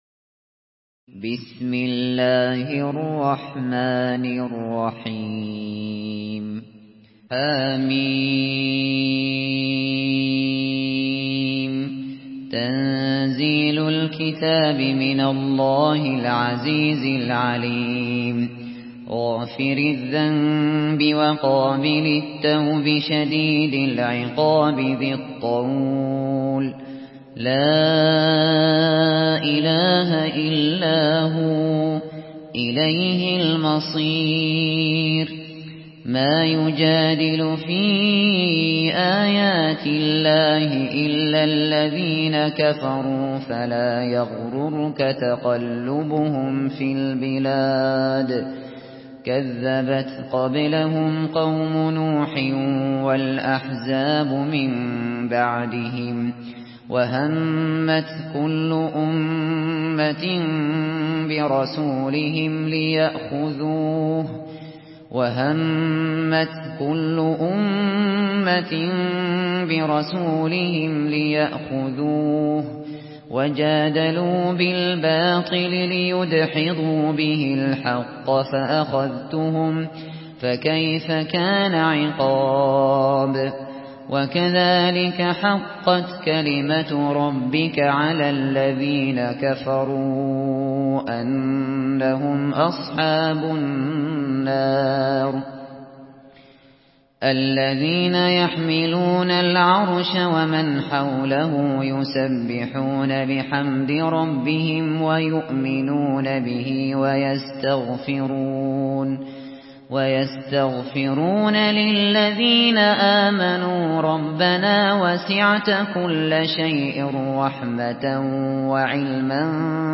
Surah গাফের MP3 by Abu Bakr Al Shatri in Hafs An Asim narration.
Murattal Hafs An Asim